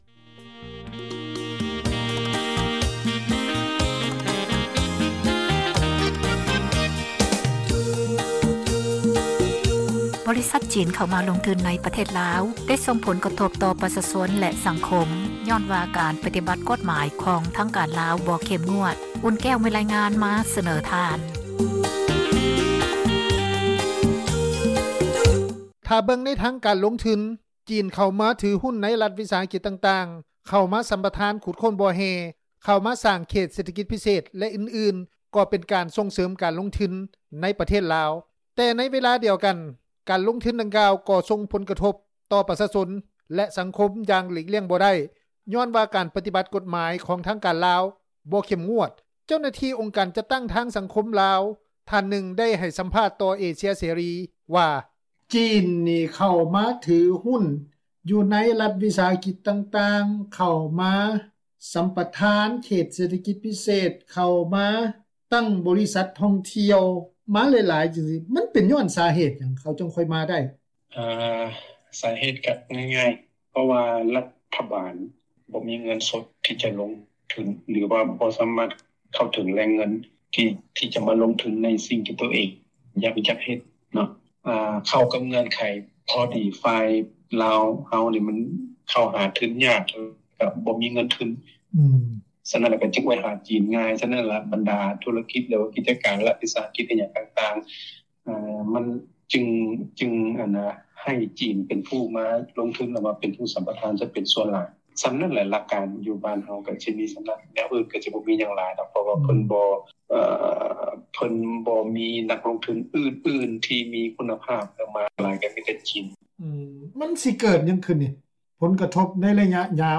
ເຈົ້າໜ້າທີ່ອົງການຈັດຕັ້ງພາກປະຊາສັງຄົມລາວ ທ່ານນຶ່ງ ໄດ້ໃຫ້ສັມພາດຕໍ່ວິທຍຸ ເອເຊັຽເສຣີ ວ່າ: